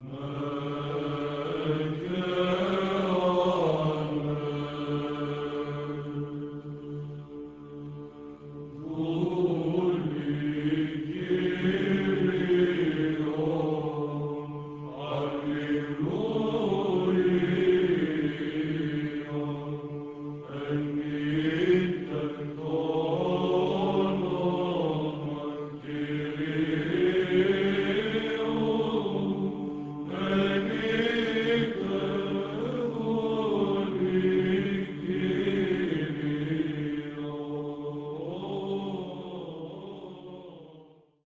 was performed on June 1998 by the University Byzantine Choir
8. Douli Kyrion, Polyeleos, Distixos, Plagal Second mode